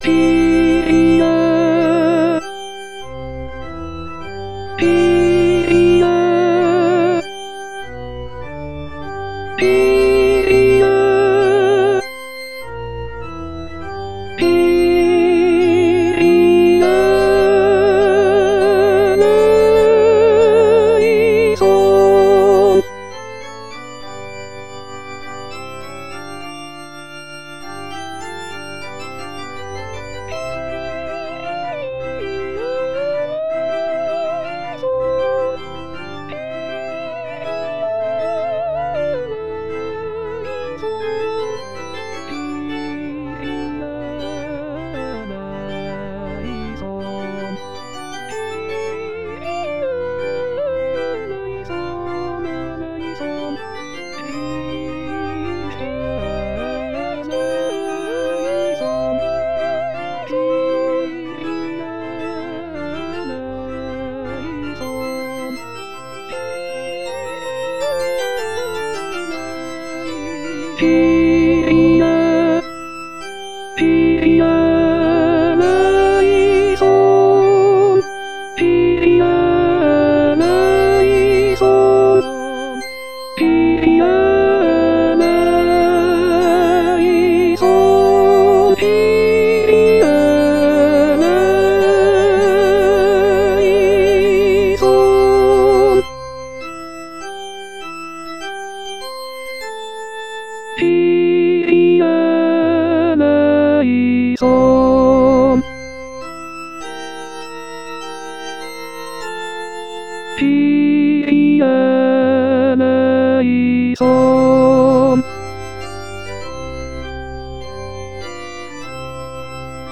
Les aides traditionnelles avec voix de synthèse
Kyrie-Alto.mp3